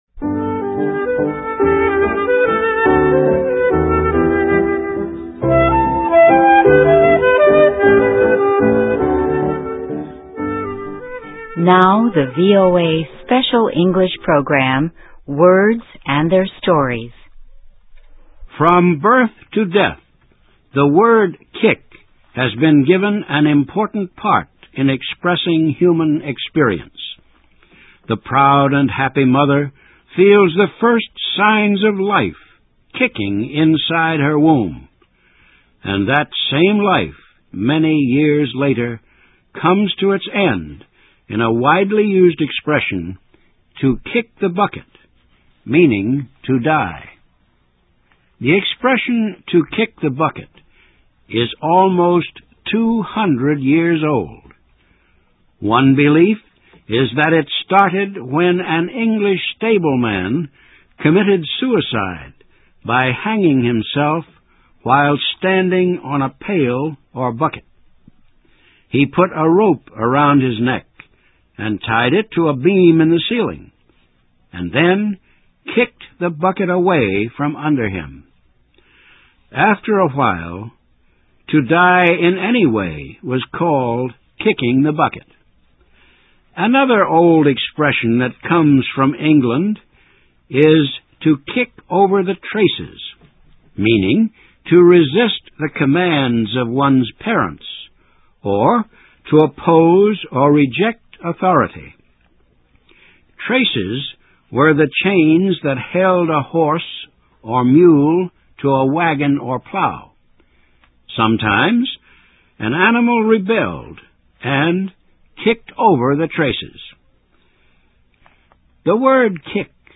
Your Position：VOA Special English > Practice your English > 与kick有关的英语成语